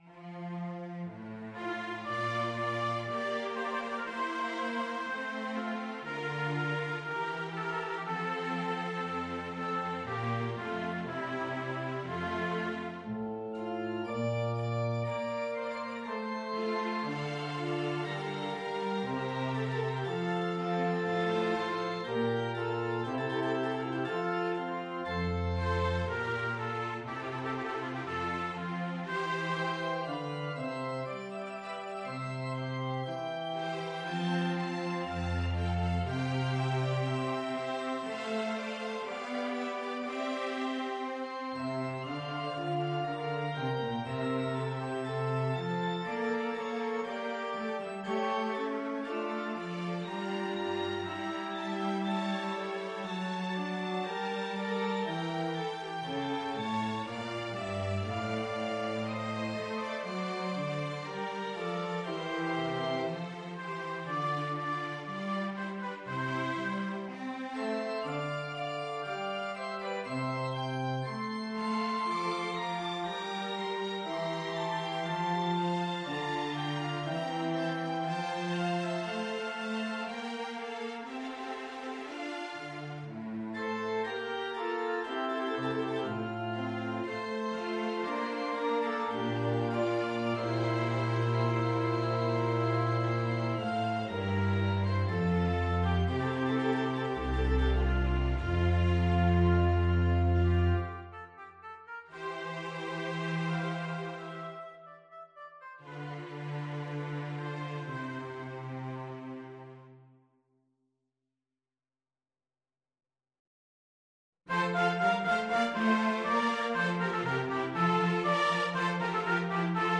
Oboe Violin 1 Violin 2 Viola Organ Bass
Instrument: Organ
Style: Classical
organ-concerto-in-f-major-hwv-295.mp3